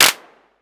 Vermona Clap 04.wav